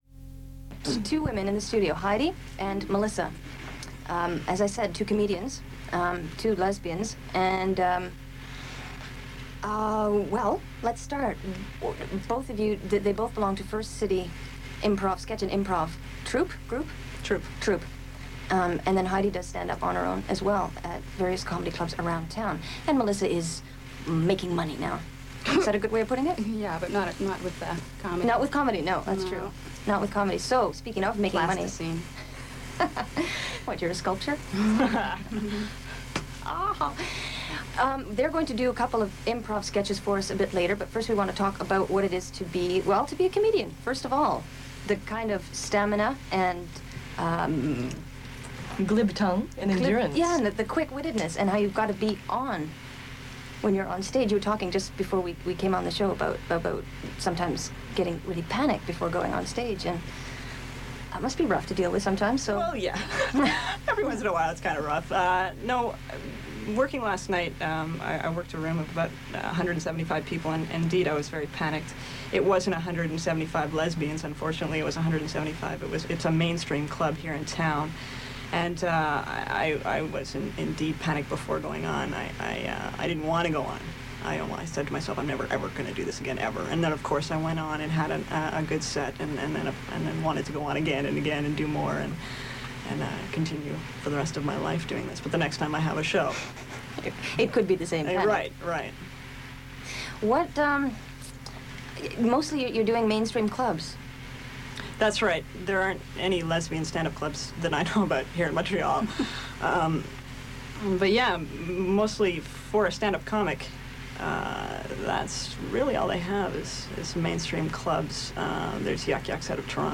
The Dykes on Mykes radio show was established in 1987.
Reels were digitized